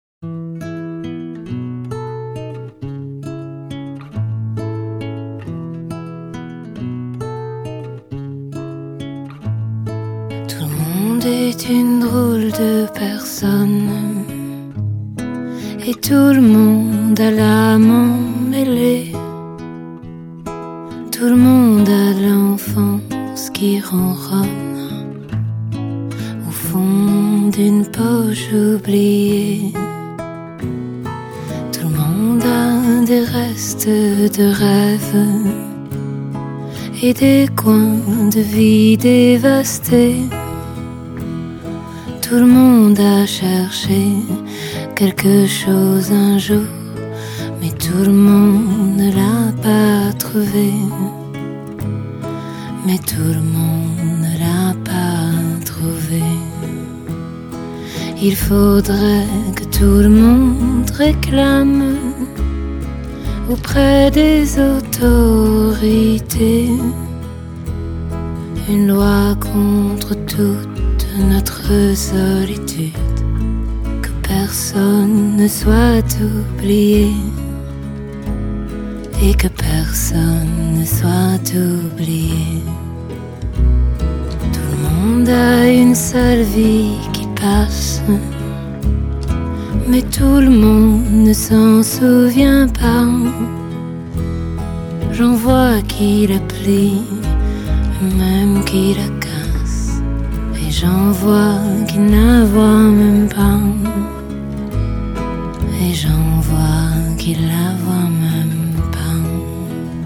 ★ 全片錄音的厚度、透明度、層次感與質感皆達示範級效果。
民謠和香頌以完美比例調和
基本上這是一張專輯，除了歌曲迷人動聽之外，音質極佳更加深了音樂本身的感染力。